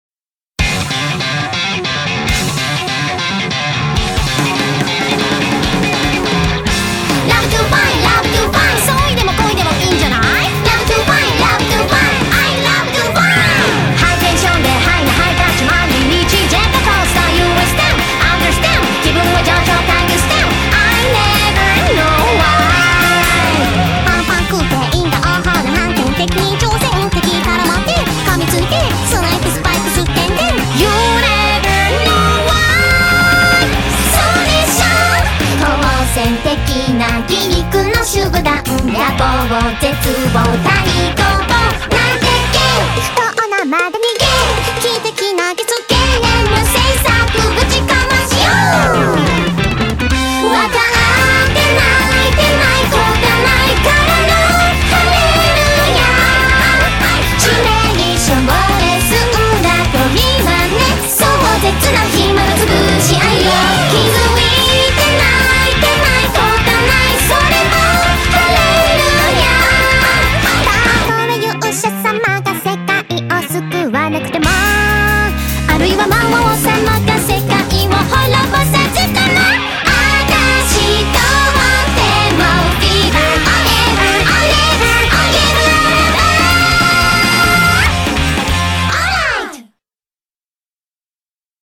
BPM143
Audio QualityPerfect (High Quality)
This song is the opening theme of this anime series.